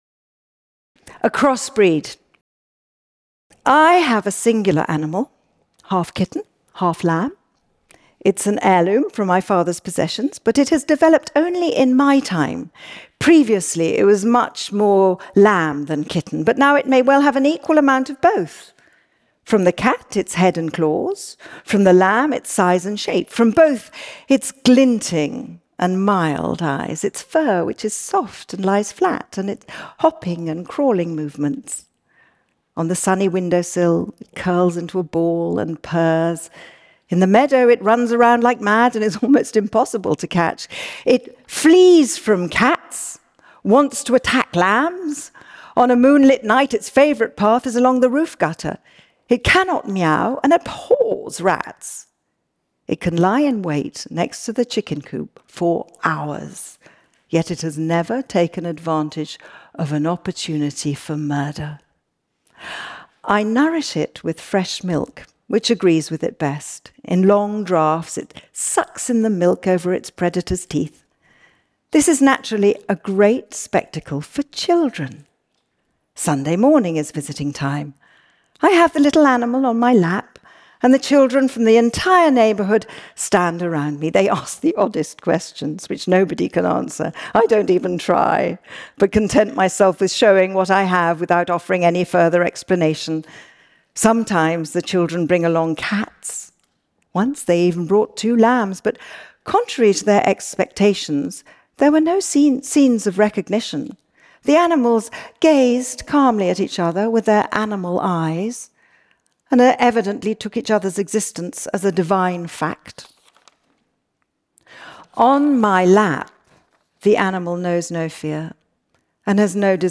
Kristin Scott Thomas Reads Kafka